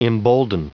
Prononciation du mot embolden en anglais (fichier audio)
Prononciation du mot : embolden